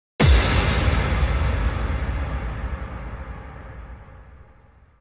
SouthSide Stomp (7) .wav